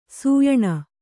♪ sūyaṇa